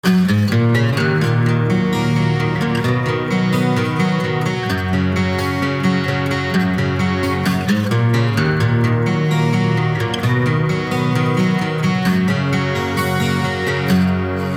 • Качество: 320, Stereo
красивые
спокойные
без слов
акустическая гитара
Начальный проигрыш без слов